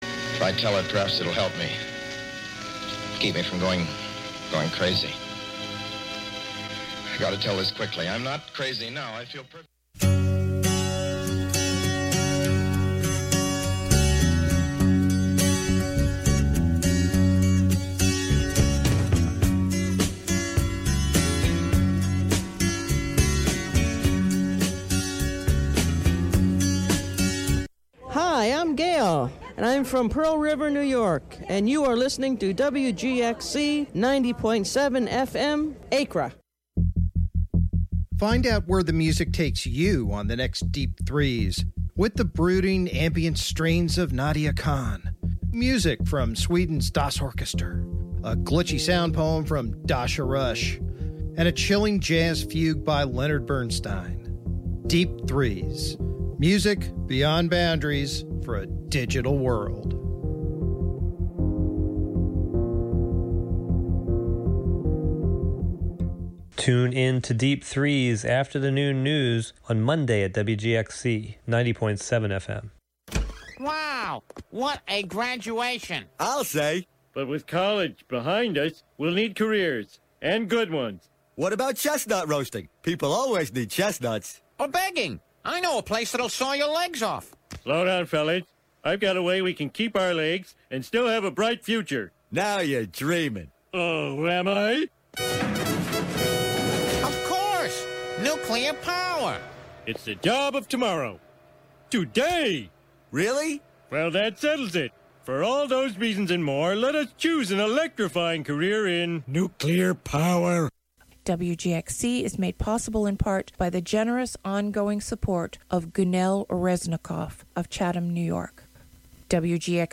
Live from the Catskill Makers space on W. Bridge St. in Catskill, a monthly show about science, technology, fixing, making, hacking, and breaking with the amorphous collection of brains comprising the "Skill Syndicate."